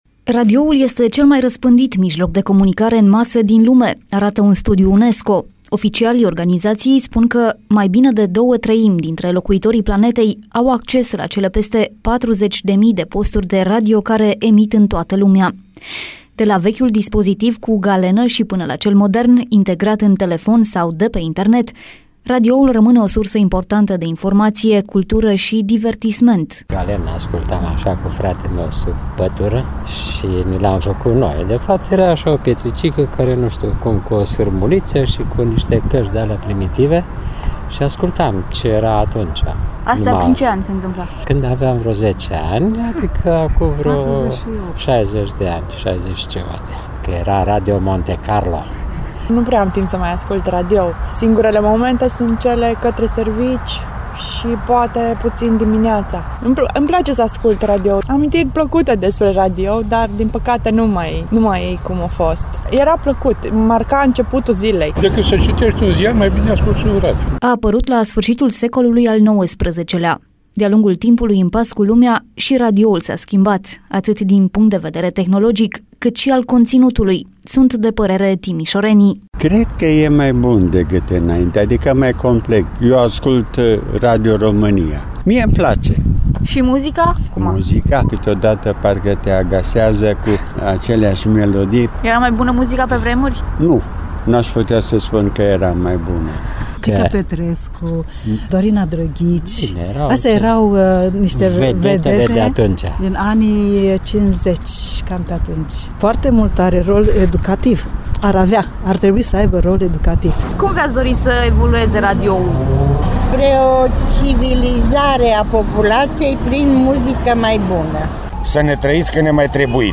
timişorenii cu care a stat de vorbă